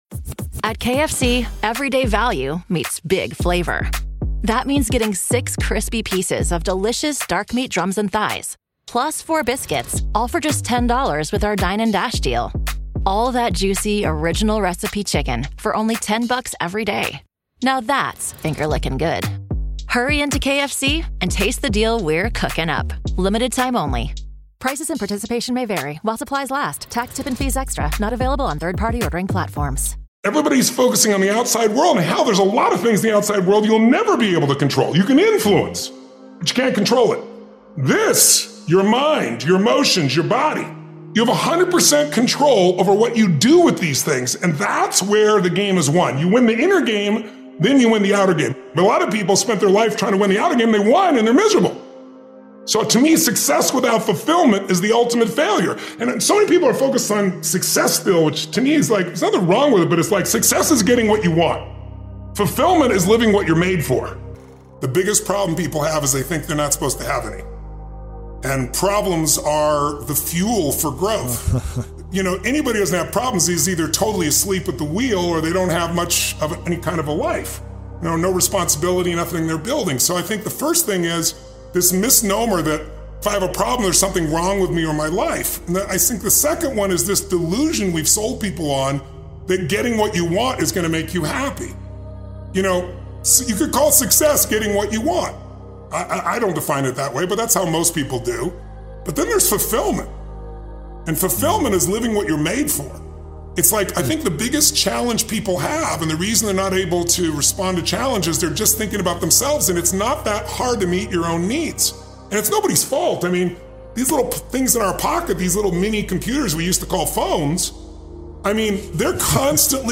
Tony Robbins - You have 100% control over your emotions motivational speech
With his electrifying energy, Robbins breaks down how to take charge of your emotional state, reframe challenges, and harness your feelings to fuel success. He shares proven strategies to shift from fear, doubt, or anger to confidence, clarity, and unstoppable drive—no matter the circumstances.